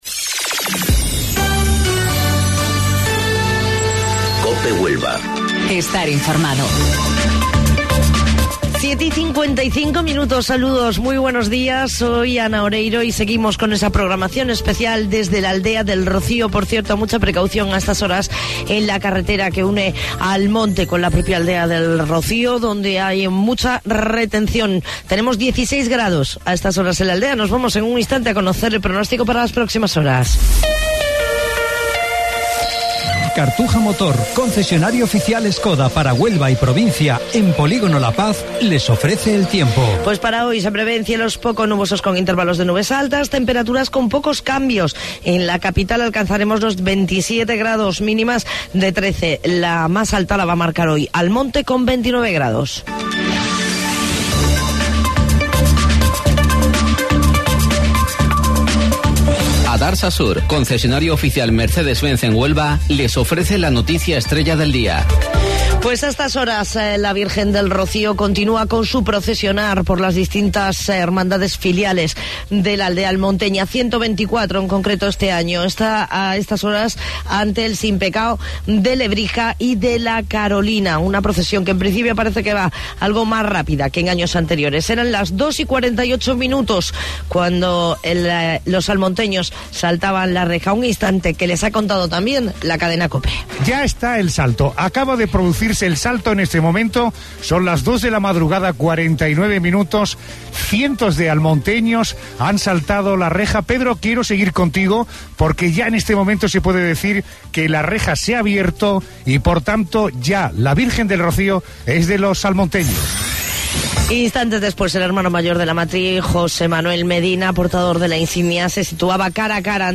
AUDIO: Informativo Local 07:55 del 10 de Junio